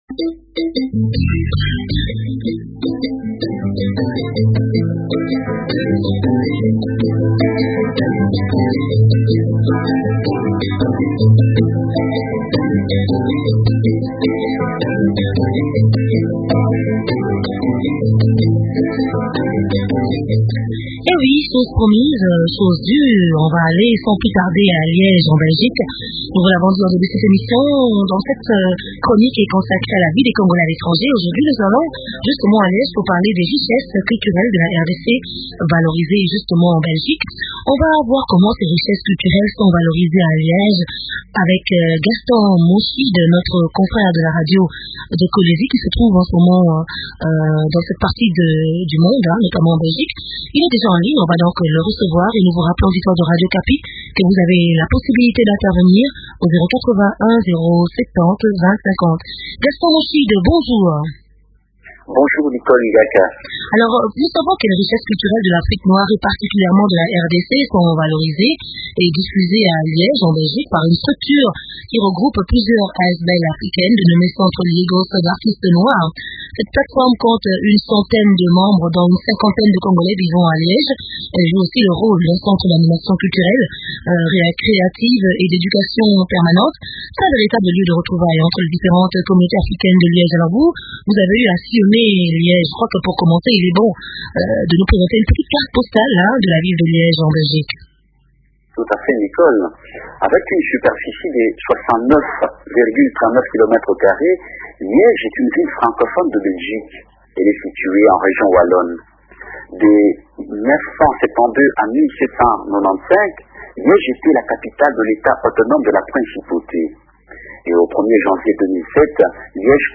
Chronique « congolais de l'étranger » : les richesses culturelles de la RDC valorisées à Liège en Belgique